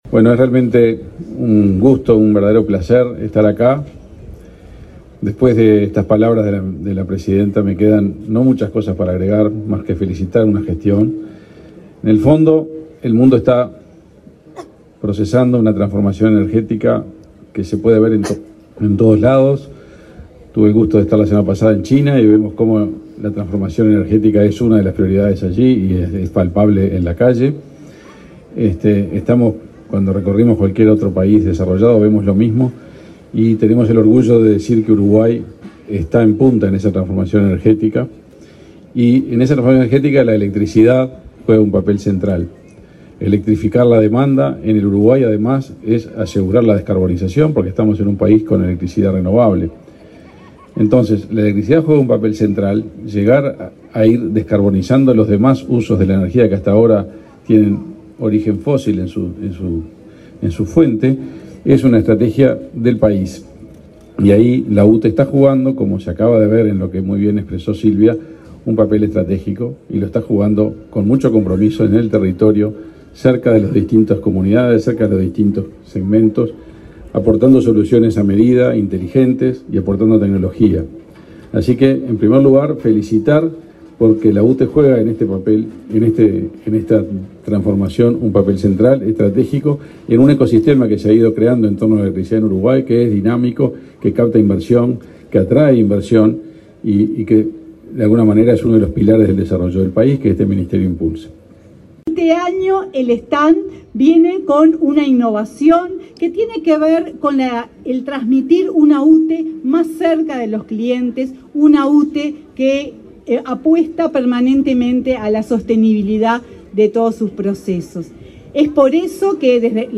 Palabras de autoridades en el stand de UTE en la Expo Prado
Este martes 12, el ministro de Industria, Omar Paganini, y la presidenta de la UTE, Silvia Emaldi, participaron en la inauguración del stand del ente